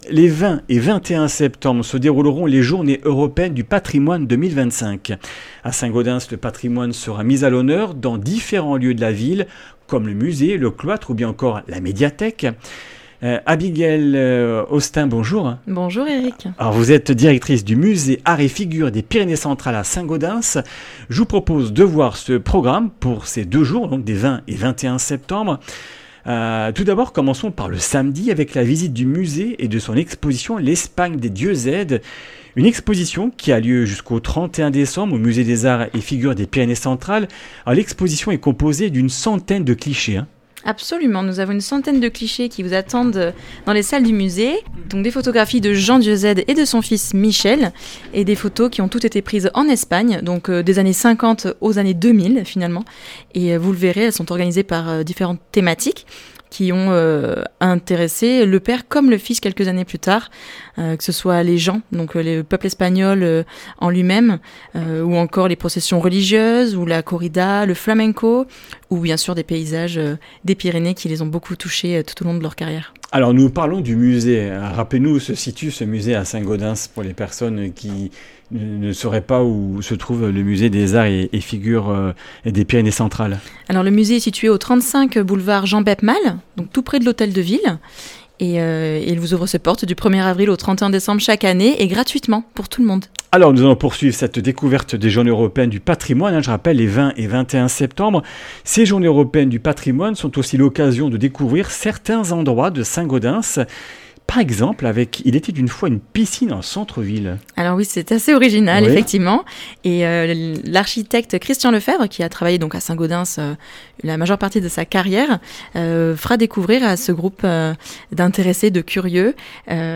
Comminges Interviews du 15 sept.
Une émission présentée par